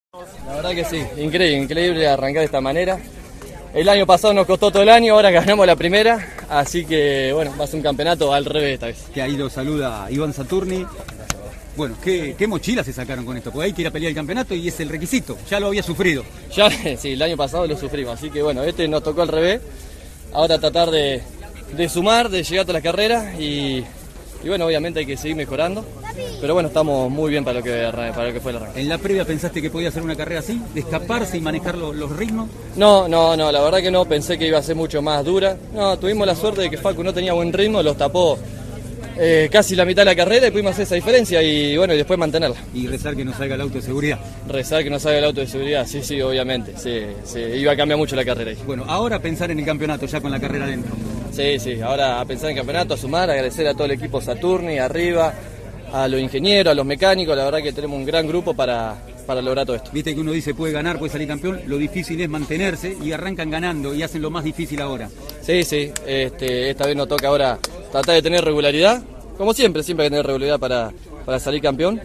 Todas las entrevistas, a continuación y en el orden antes mencionado: